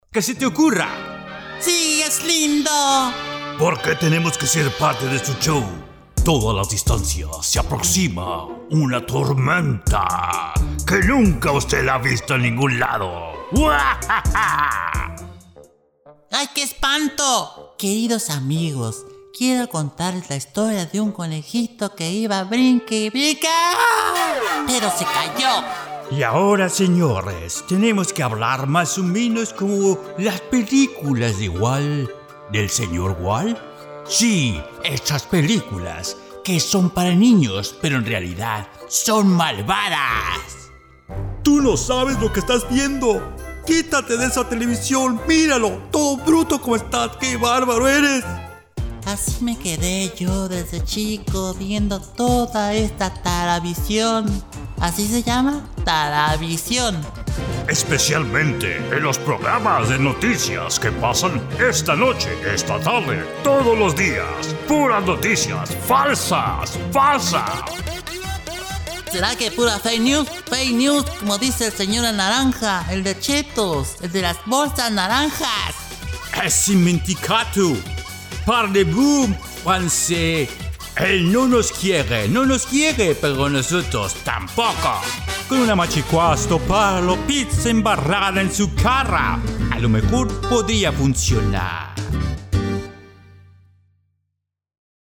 Male
Voice over Talent with Deep , nice and friendly , Spanish accent for english spots and translations.
Words that describe my voice are Deep, Comercial, promo.
All our voice actors have professional broadcast quality recording studios.
0515Character_Voices.mp3